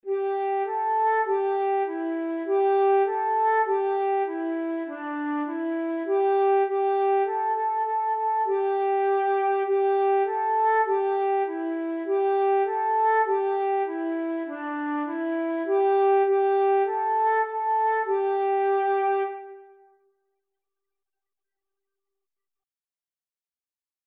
dit liedje is pentatonisch